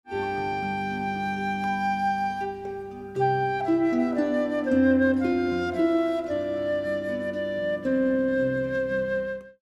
flute and harp